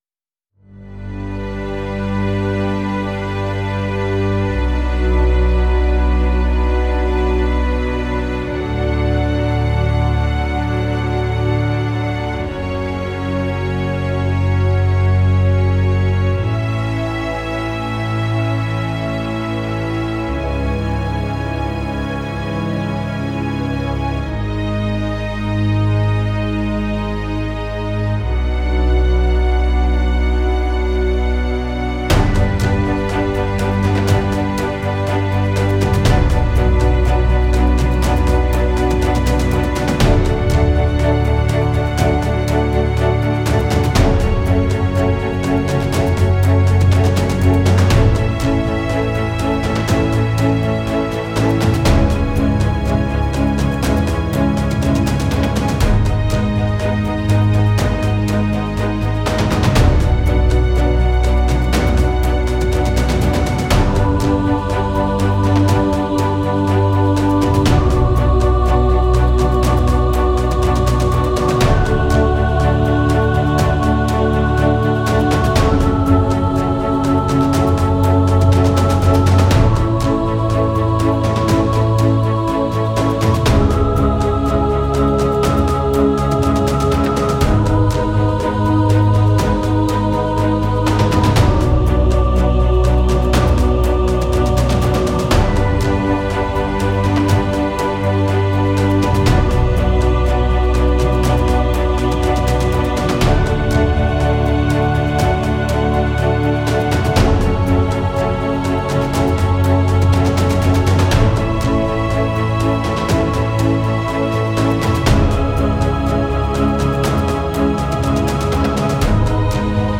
это мощная композиция в жанре пост-рок